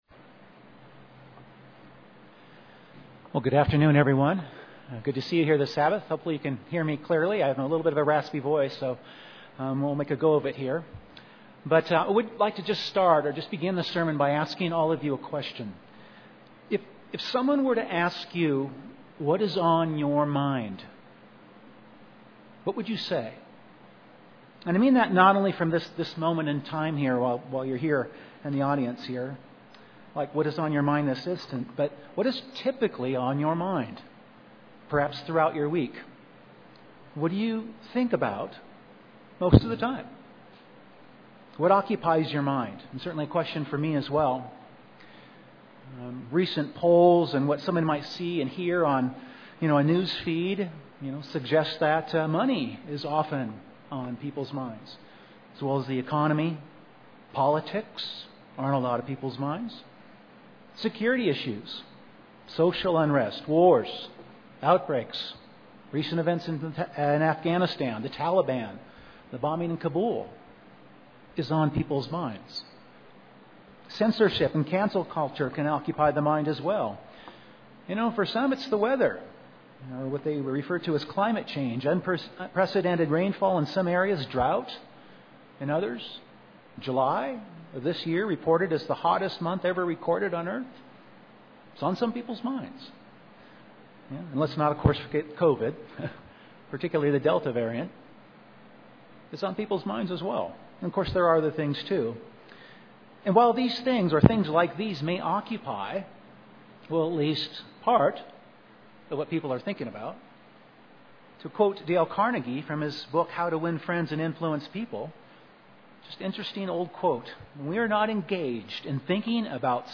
And to include in our meditation a focus on things that are noble, just, pure, lovely, of good report; things of virtue and worthy of praise (Phil. 4:8). This sermon will review and explore meditation as a very important tool or way that God has given to help us with our calling, to act on our calling, and in terms of what should be on our minds.